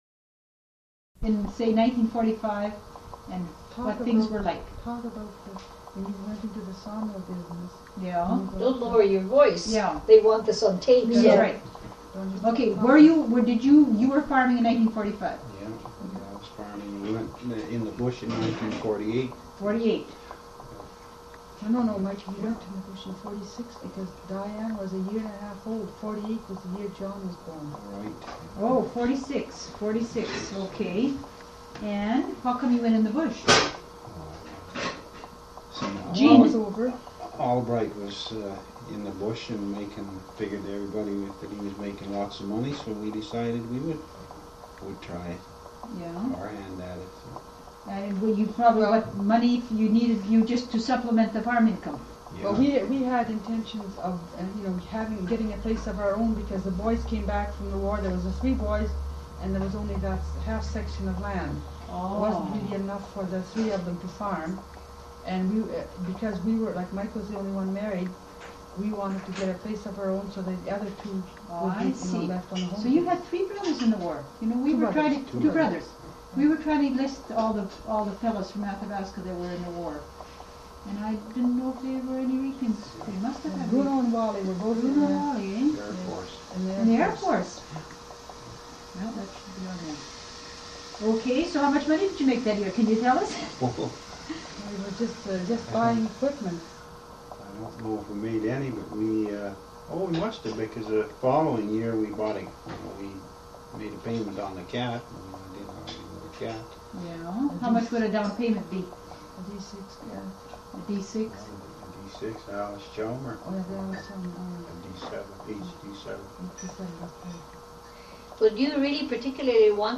Audio Discussion